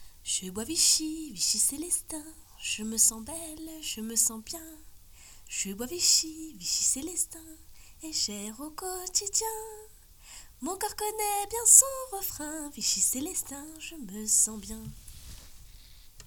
Voix off
voix off dessins animés